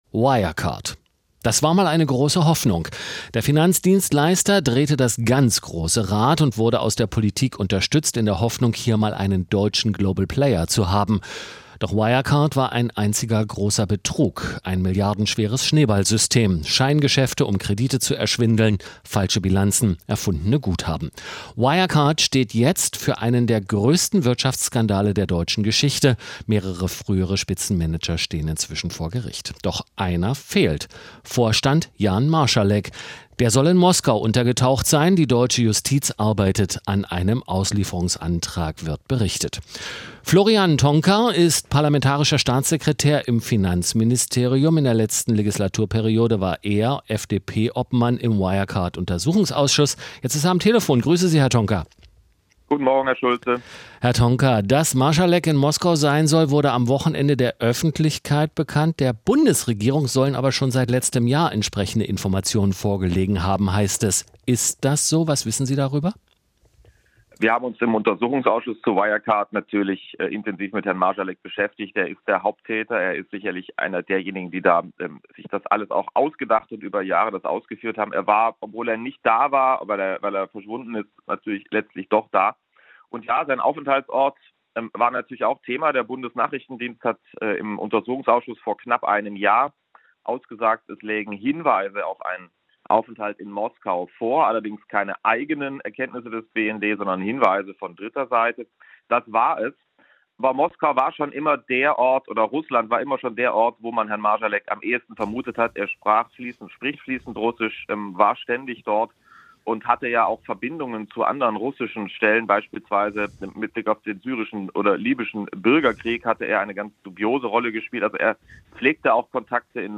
radio interview mit sts toncar--marsalek auslieferung schwer.... ach was !!!!.... die verarsche geht weiter...